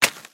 Звуки хруста чипсов
Все звуки записаны в высоком качестве и доступны бесплатно.
Шум упавшей пачки, Шорох чипсов на полу, Рассыпанные хрустящие закуски, Звук разлетевшихся чипсов